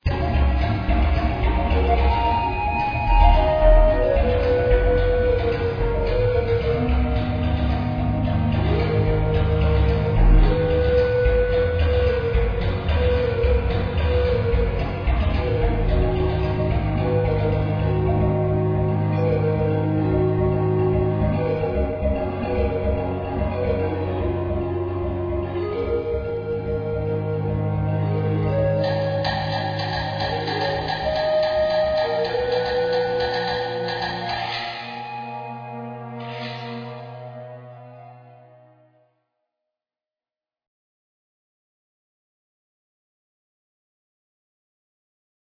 African music theme.
Jungle tracks with beat.